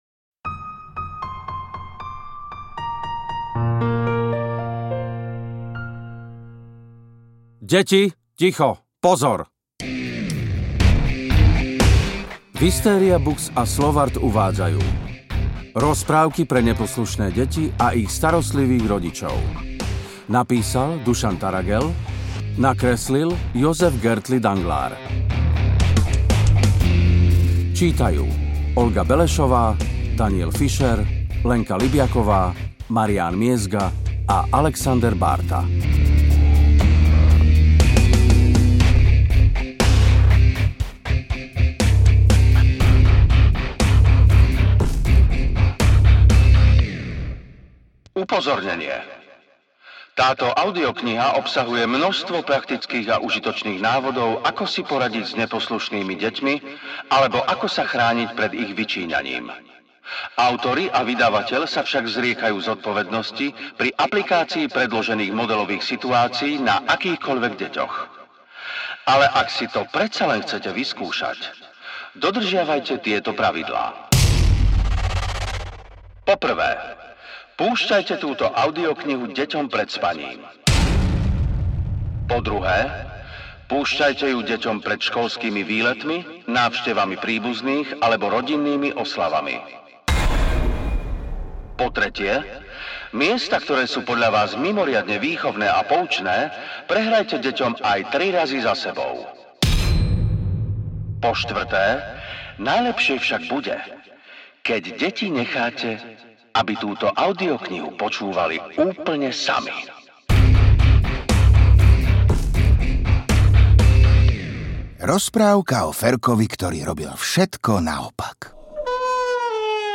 Rozprávky pre neposlušné deti a ich starostlivých rodičov audiokniha
Ukázka z knihy